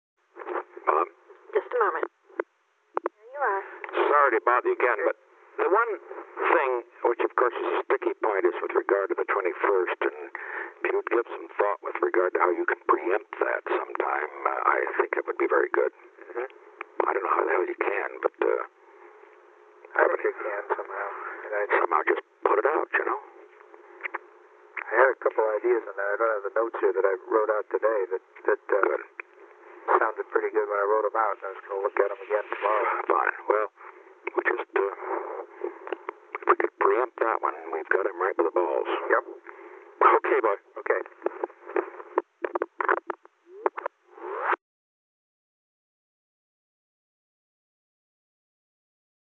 Location: White House Telephone
The President talked with the White House operator.
Incoming telephone call
The President talked with H. R. (“Bob”) Haldeman.